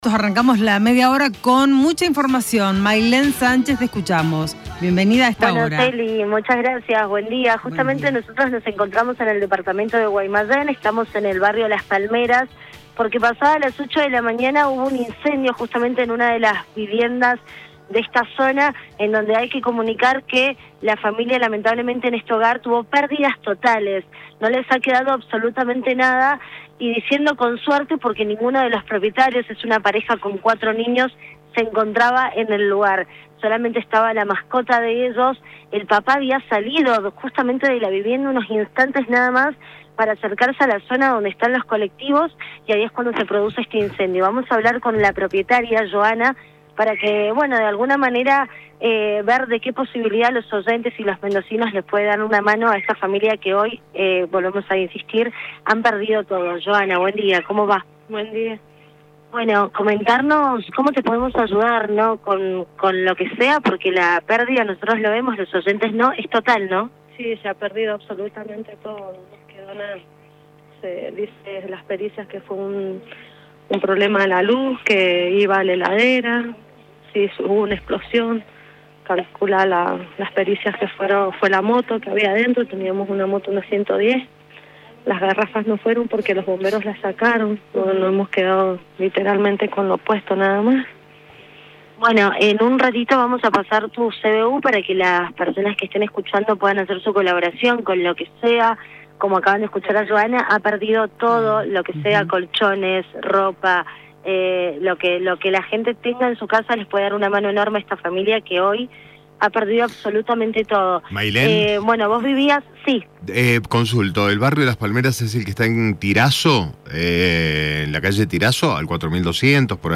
LVDiez - Radio de Cuyo - Móvil de LVDiez desde Guaymallén